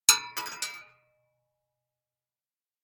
Bullet Shell Sounds
rifle_metal_7.ogg